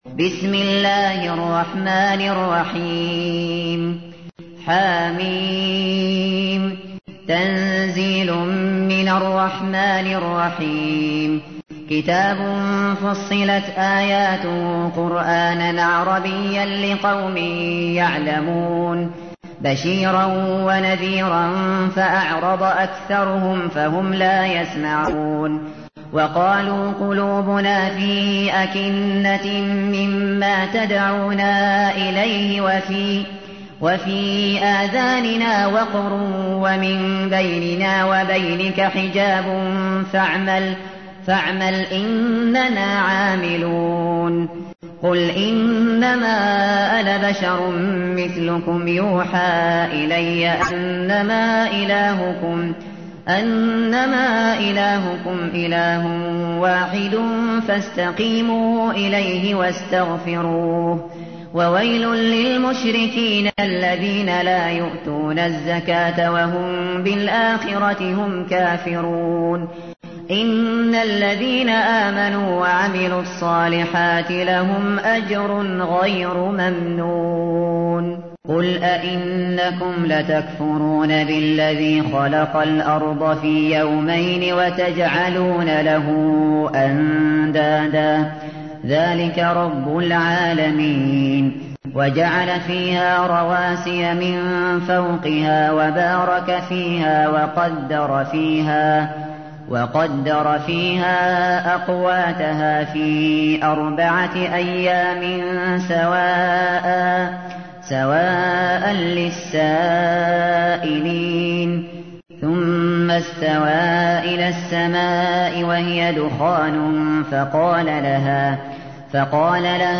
تحميل : 41. سورة فصلت / القارئ الشاطري / القرآن الكريم / موقع يا حسين